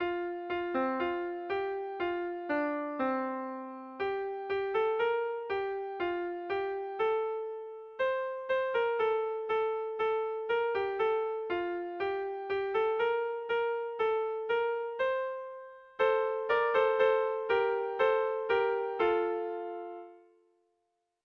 Gabonetakoa
ABDE